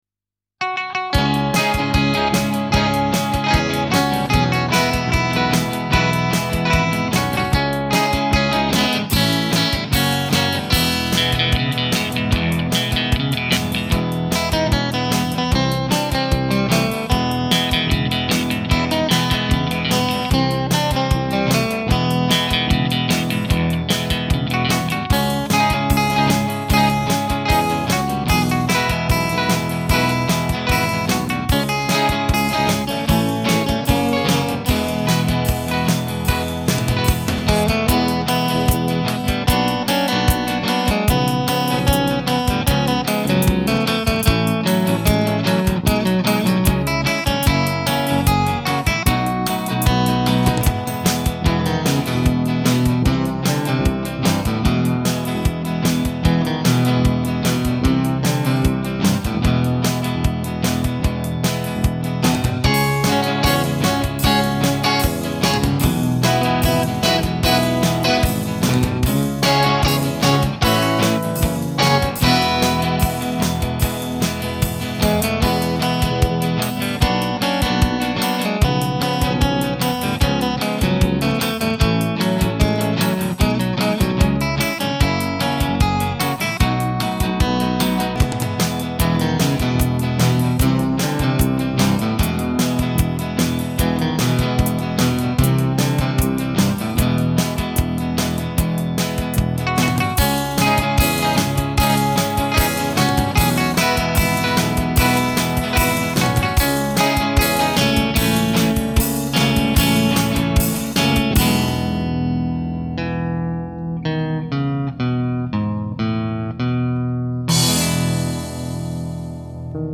Basu jsem osadil hlazenými strunami pro zachování měkkého zvuku. Výsledkem je krásný nástroj se zvukem blízkým Basoře.